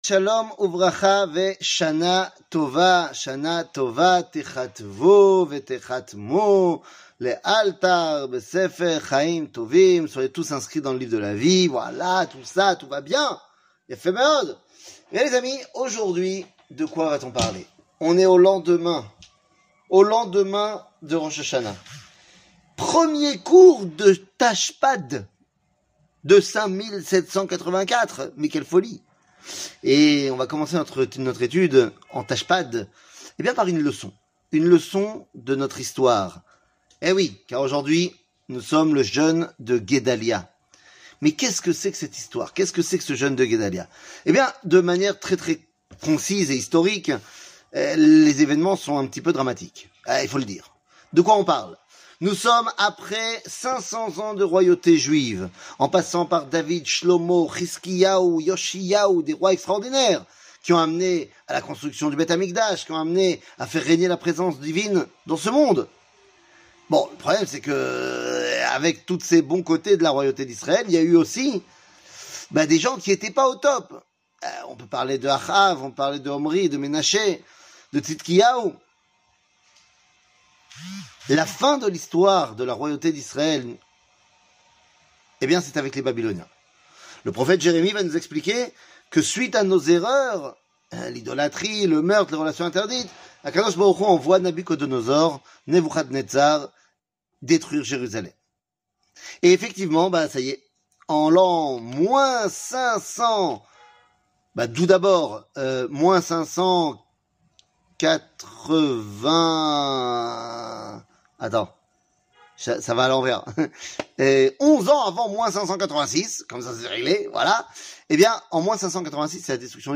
Le jeune de Guedalia, Une lecon d'histoire 00:08:03 Le jeune de Guedalia, Une lecon d'histoire שיעור מ 18 ספטמבר 2023 08MIN הורדה בקובץ אודיו MP3 (7.36 Mo) הורדה בקובץ וידאו MP4 (12.72 Mo) TAGS : שיעורים קצרים